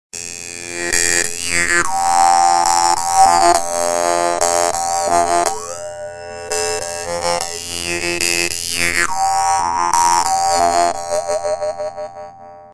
Die vietnamesischen Maultrommeln zeichnen sich durch ihre einfache Spielbarkeit, ihren schönen, obertonreichen Klang und den günstigen Preis aus.
Dabei erinnert ihr Sound an elektronische Klänge analoger Synthesizer – ganz ohne Technik.
Hörprobe Dan Moi Standard 1: